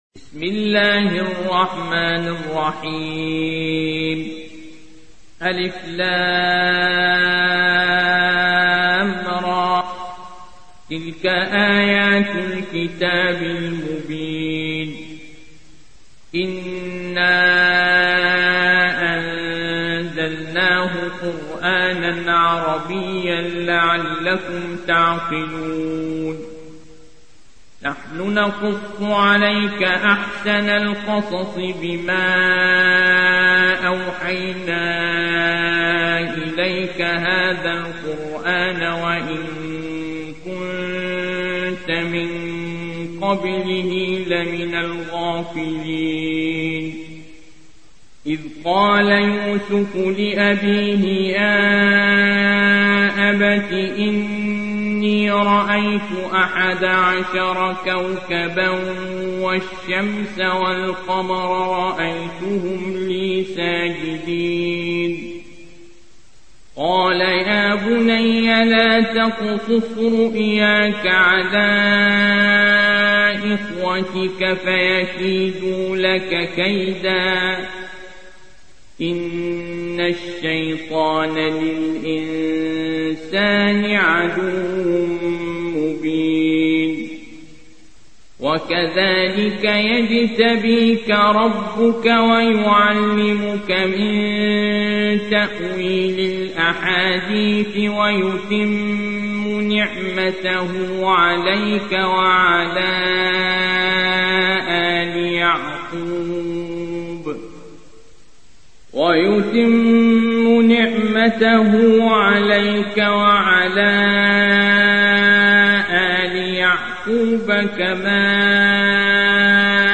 روایت حفص از عاصم